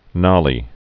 (nŏlē)